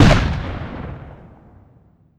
cannonShot.wav